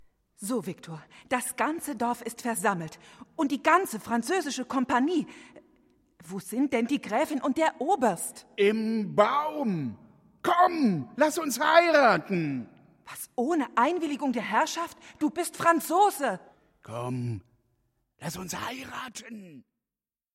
Die vorliegende Einspielung beruht auf der Uraufführung des gesamten Werks bei der Theater&Philharmonie Thüringen und wurde im September 2008 im Konzertsaal der Bühnen der Stadt Gera aufgenommen.